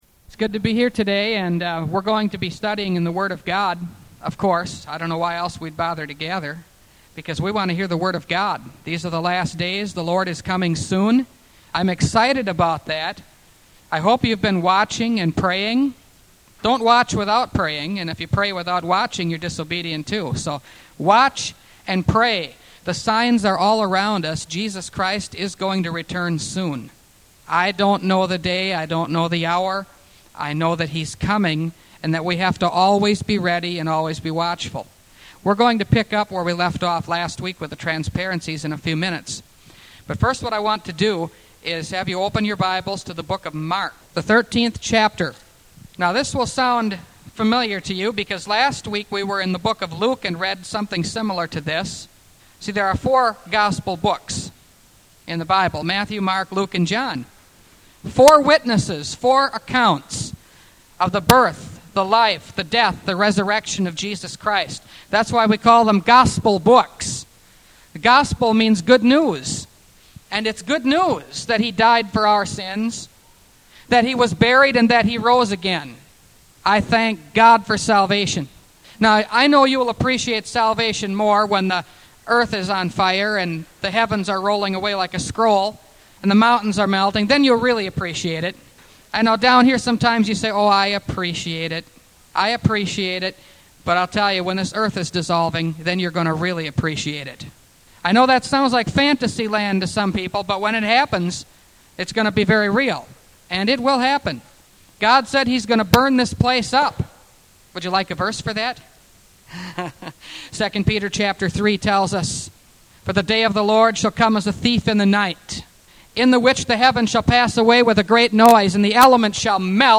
Revelation Series – Part 28 – Last Trumpet Ministries – Truth Tabernacle – Sermon Library